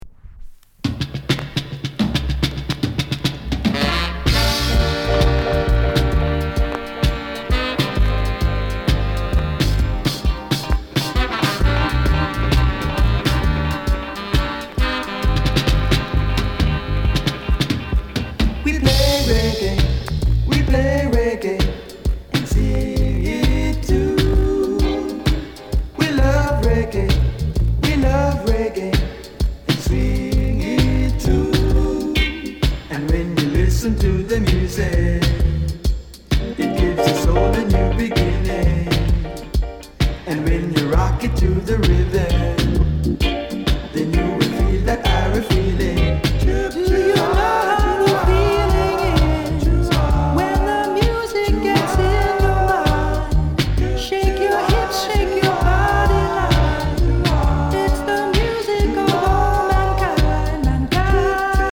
��VG�� �٤��ʽ�����ޤ����ɹ������Ǥ��� ������ NICE LOVERS ROCK